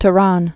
(tə-rän), Strait of